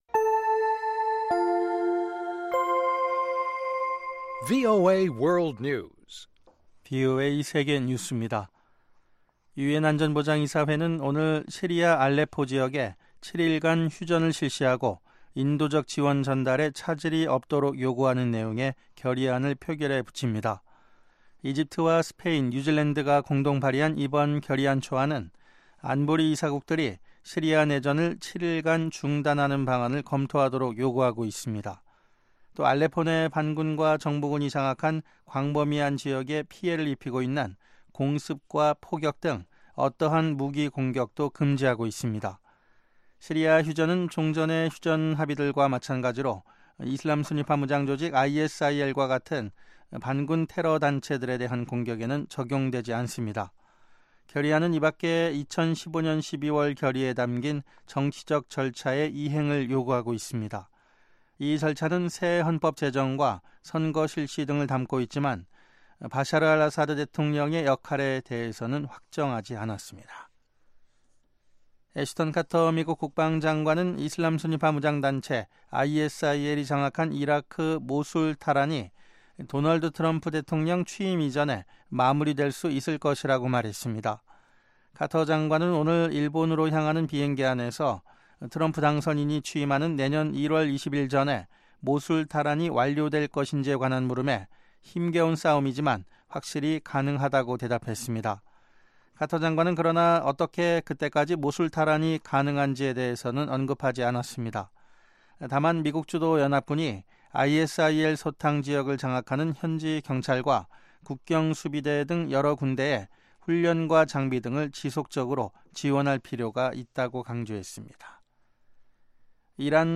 VOA 한국어 방송의 간판 뉴스 프로그램 '뉴스 투데이' 3부입니다.